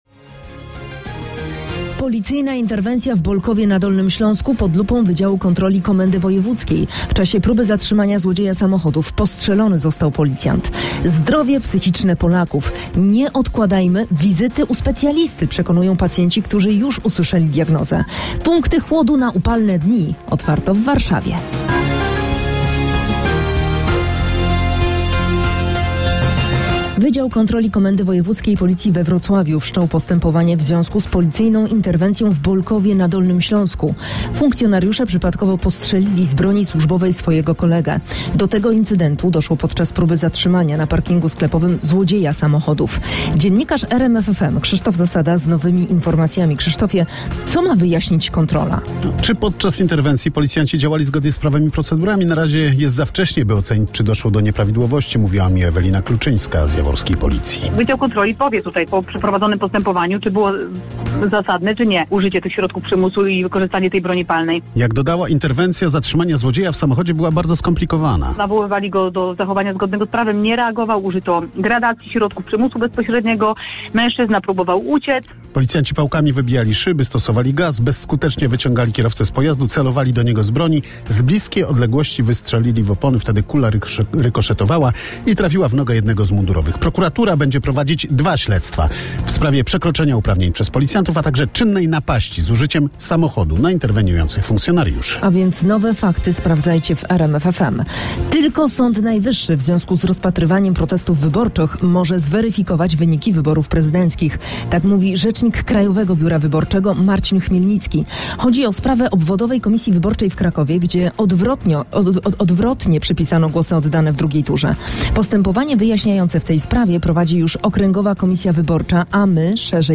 Najświeższe wiadomości z kraju i świata przygotowywane przez dziennikarzy i korespondentów RMF FM. Polityka, społeczeństwo, sport, kultura, ekonomia i nauka. Relacje na żywo z najważniejszych wydarzeń.